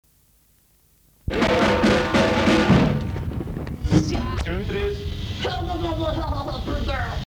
fart